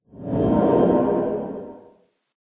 Sound / Minecraft / ambient / cave / cave12.ogg
cave12.ogg